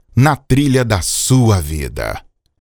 Vinheta